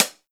Closed Hats
HIHAT_DONT_FUCK_WIT_ME.wav